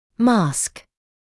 [mɑːsk][маːск]маска; маскировать, скрывать